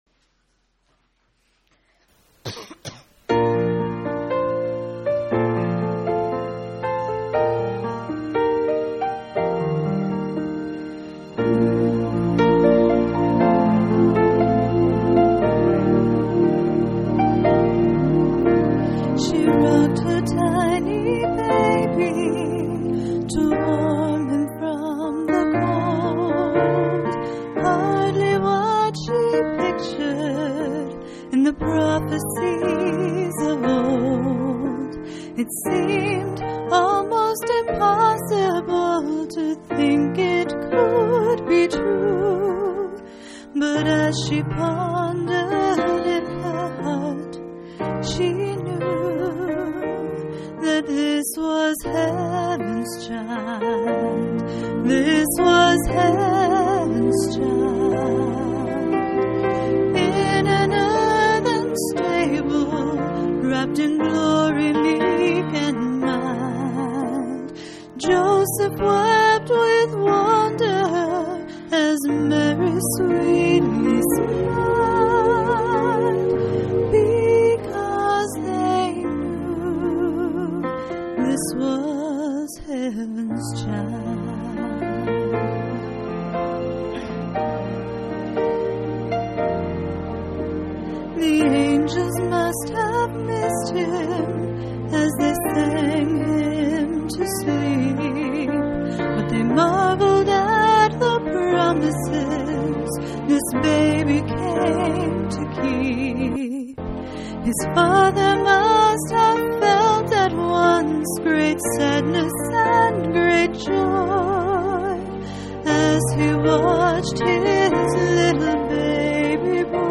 12/18/2005 Location: Phoenix Local Event